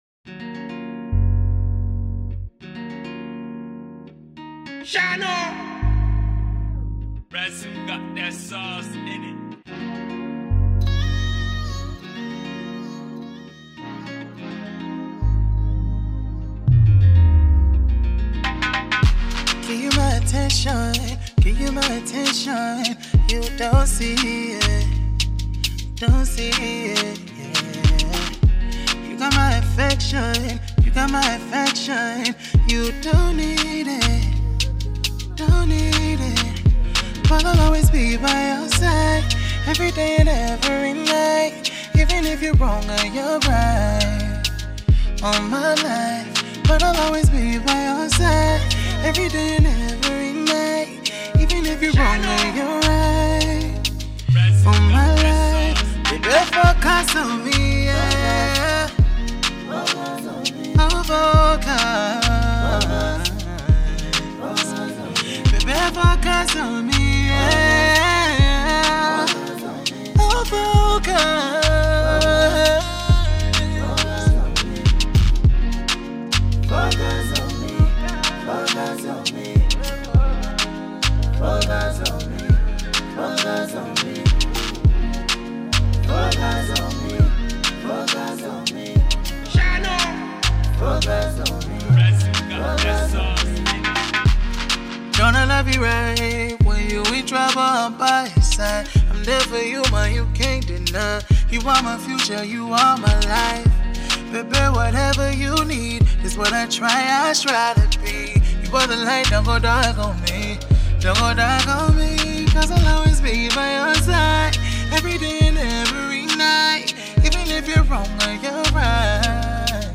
RnB
an RnB and colloquial mix